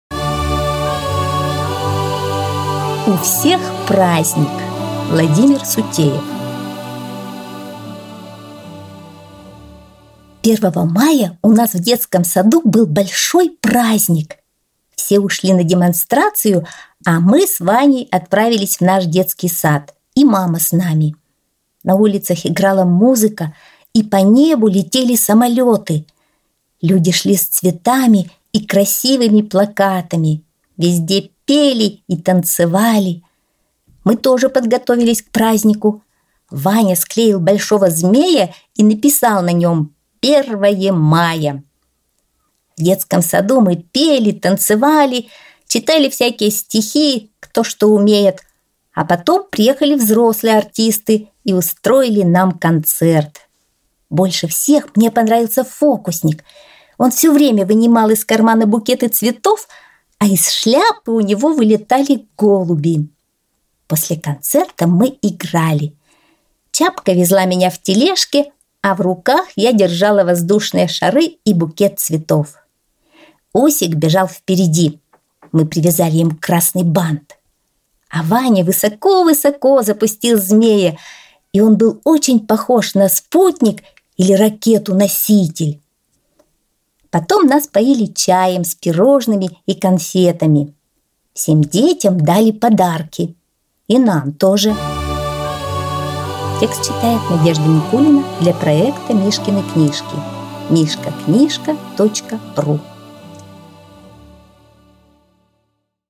Аудиосказка «У всех праздник »